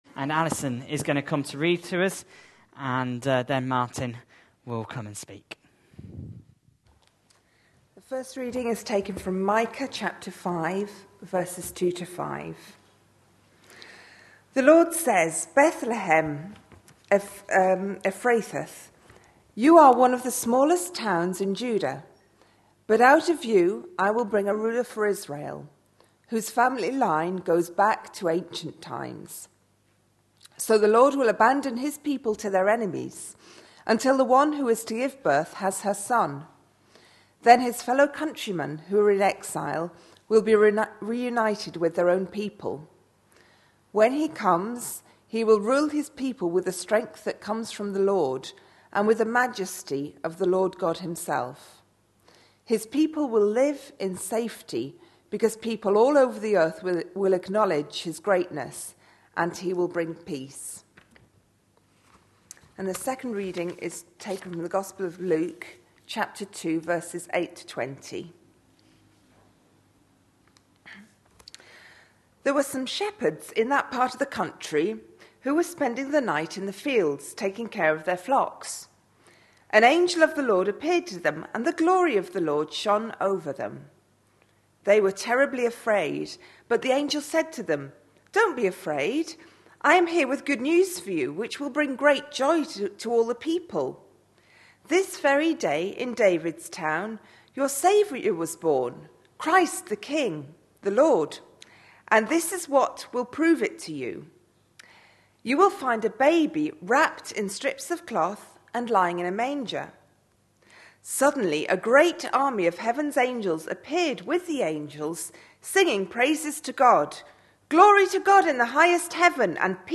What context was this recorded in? Micah 5:2-5 Listen online Details Readings are Micah 5:2-5 and Luke 2:8-20. This was the evening service for the 3rd Sunday in Advent.